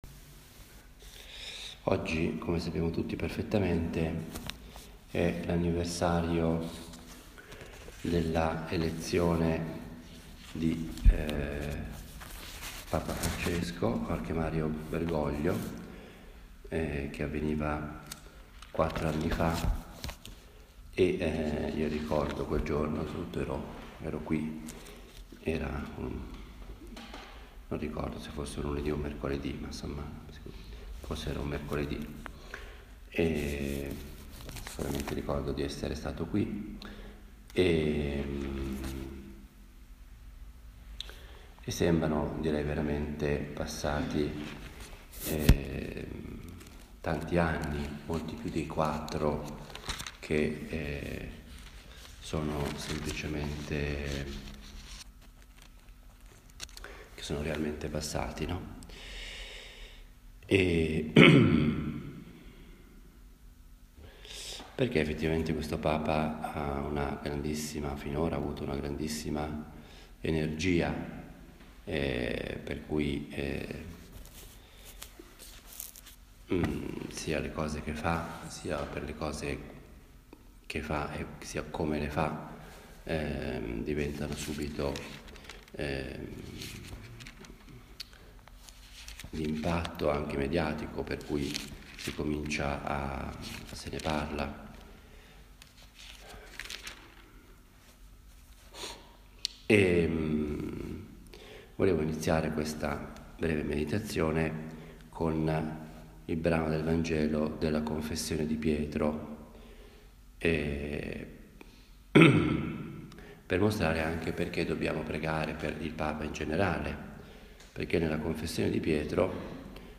Una “meditazione” è un genere omiletico diverso dalla predica, dal discorso, o dall’allocuzione. Ha il carattere piano, proprio di una conversazione familiare e io la intendo come il mio dialogo personale – fatto ad alta voce – con Dio, la Madonna, ecc.
Le meditazioni che si trovano sul blog sono semplici registrazioni – senza nessuna pretesa particolare – di quelle che faccio abitualmente.